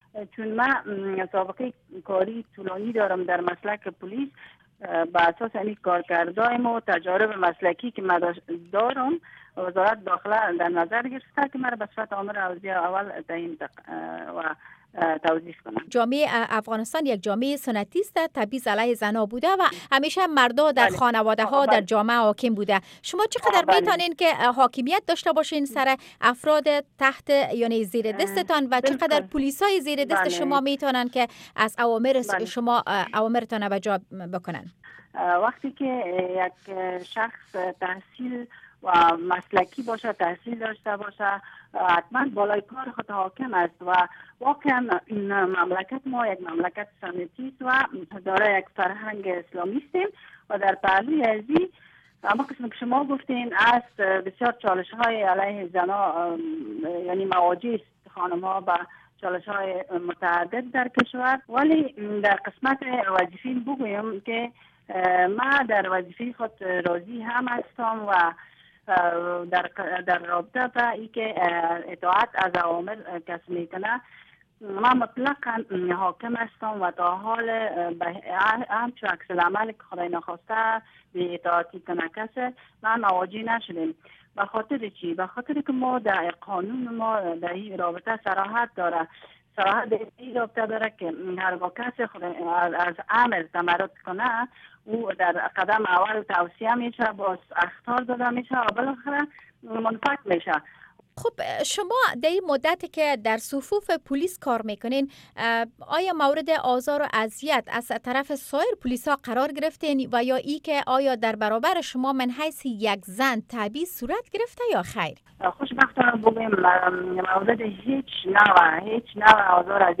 مصاحبه ها
گفت و گو